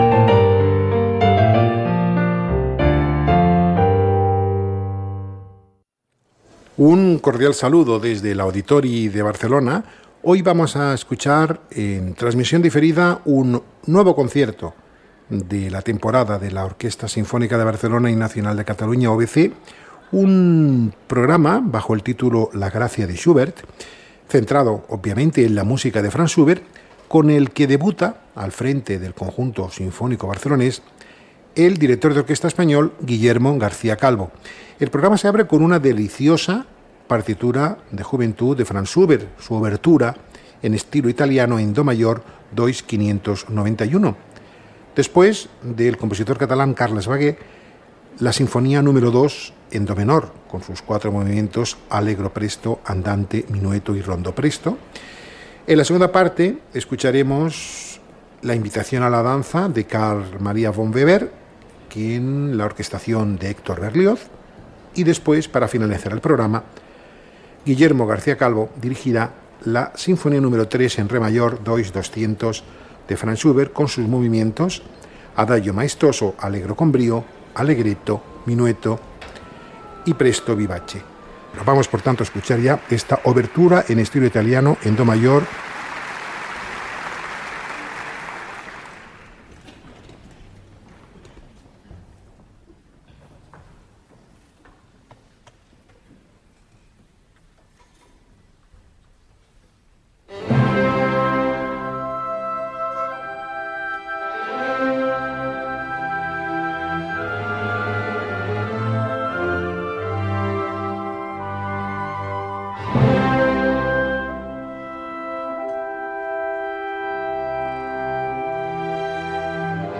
Over to Spain this week for a concert by Orquesta Sinfonica de Barcelona Y Nacional de Cataluña, conducted by the extremely popular star-on-the-rise Guillermo Garcia-Calvo at the helm and recorded live by Radio Nacional España on May 6, 2012.